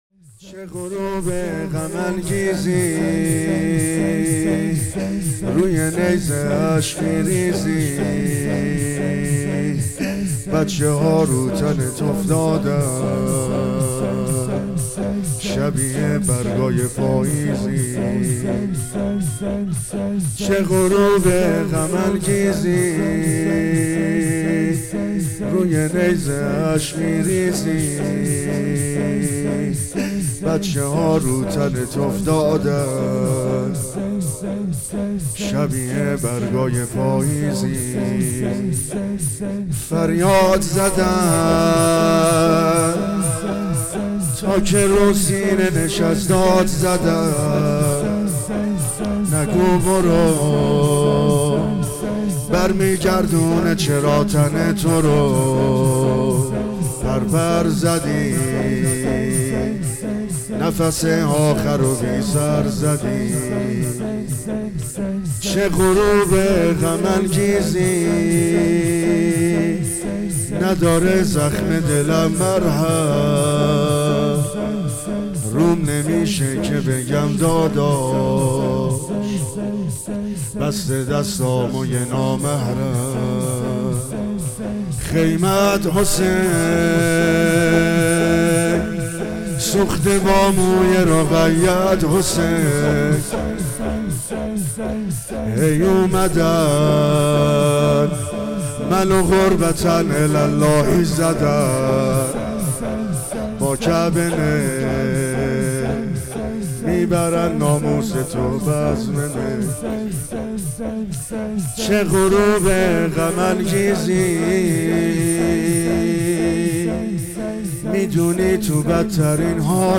مداحی حاج عبد الرضا هلالی | اجتماع عزاداران امیرالمومنین علی (ع)| هیئت ریحانه النبی (س) تهران | شهادت امیرالمومنین علی (ع) | پلان 3